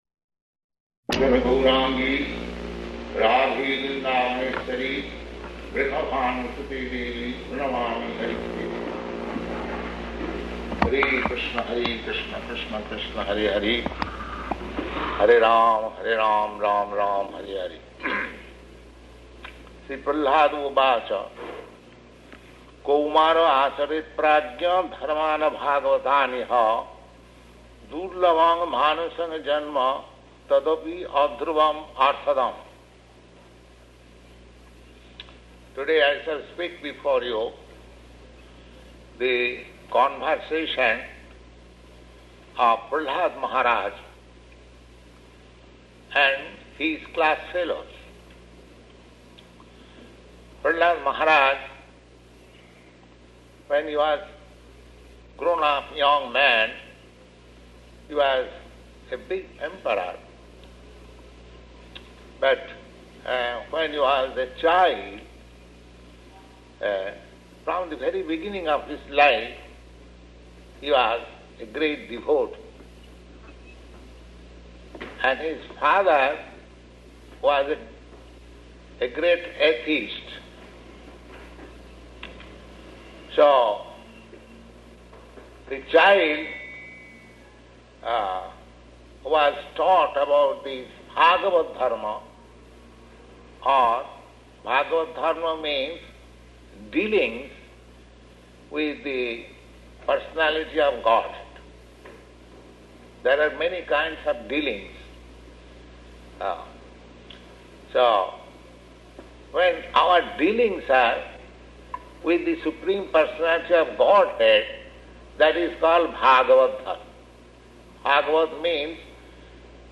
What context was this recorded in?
Location: San Francisco